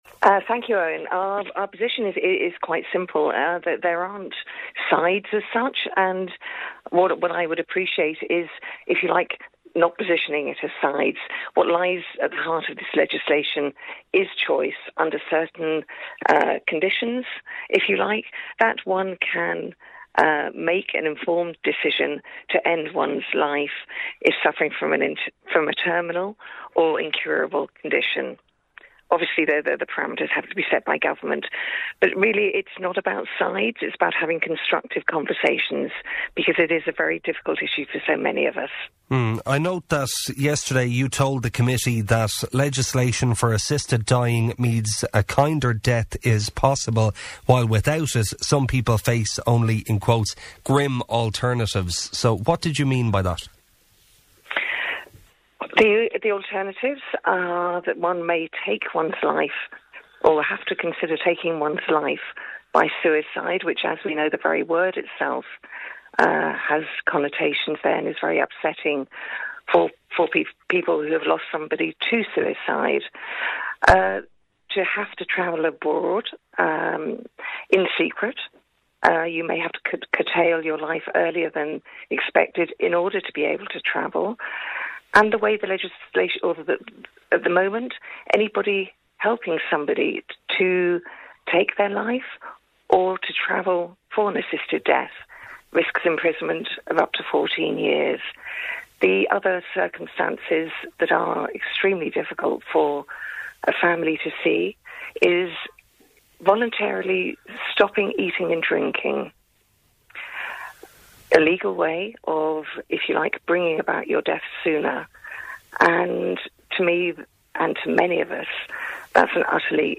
Listen to this morning's debate on the show below.